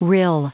Prononciation du mot rill en anglais (fichier audio)
Prononciation du mot : rill